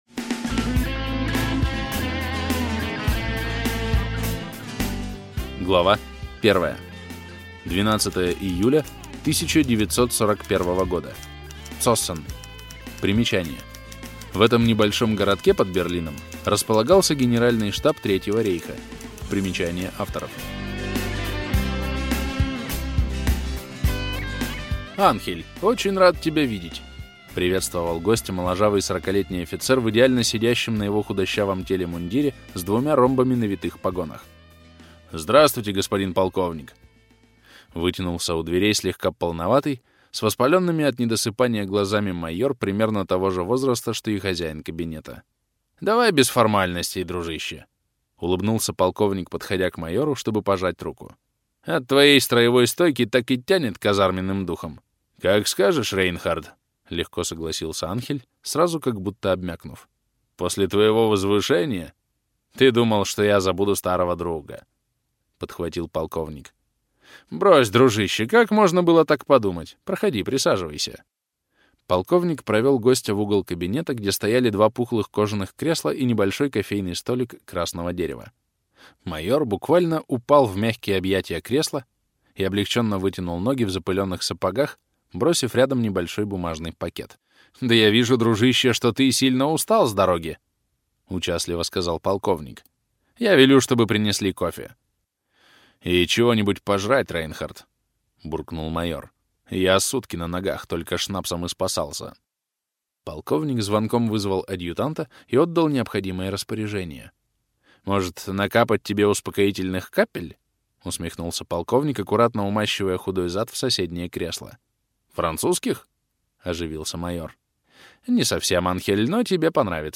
Аудиокнига Встреча с Вождем | Библиотека аудиокниг